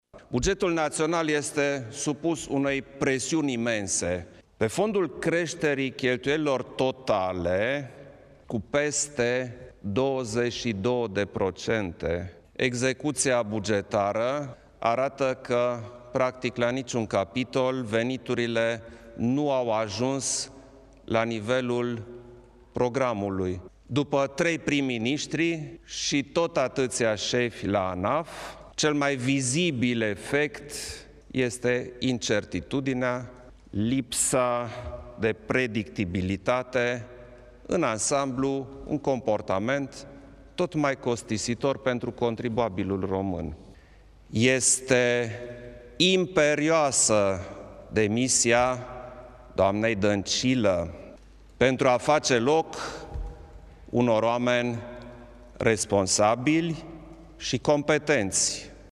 Într-o declarație de presă, susținută la platul Cotroceni, șeful statului a criticat dur execuția bugetară pe primul trimestru, arătând că se înregistrează o contraperformanță a guvernării PSD fiind prima dată după criză când veniturile fiscale au scăzut.